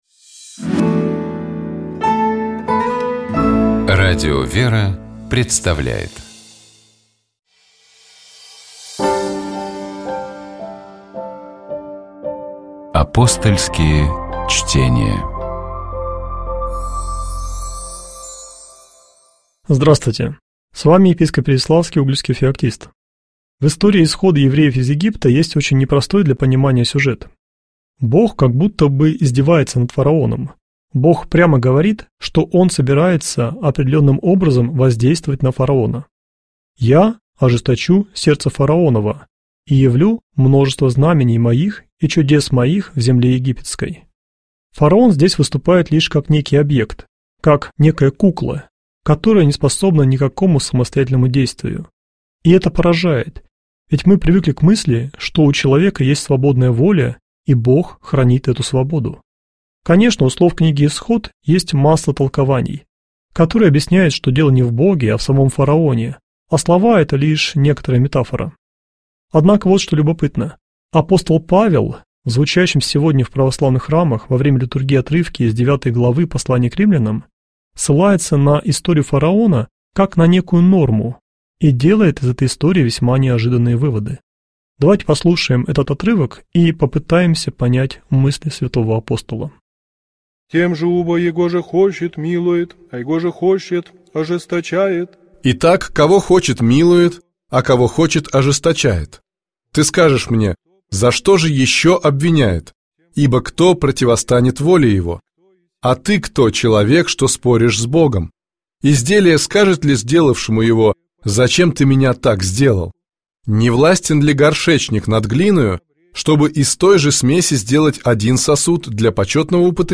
Богослужебные чтения - Радио ВЕРА